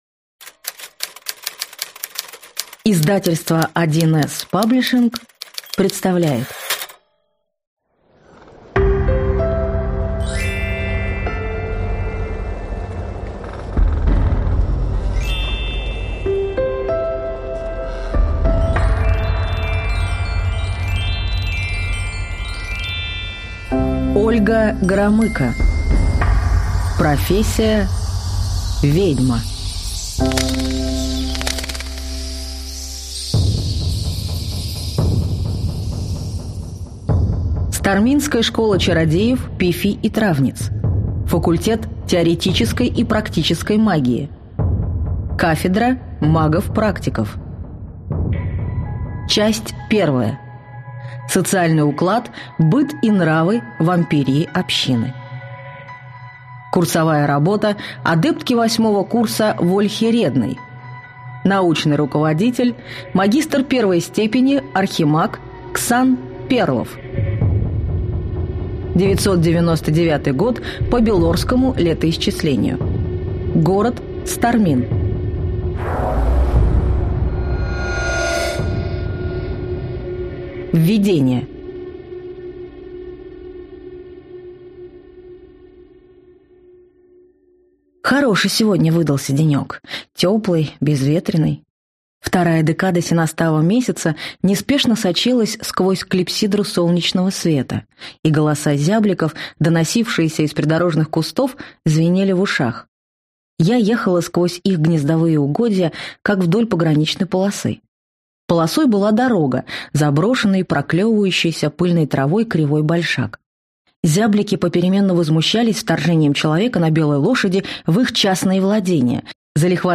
Аудиокнига Профессия: ведьма - купить, скачать и слушать онлайн | КнигоПоиск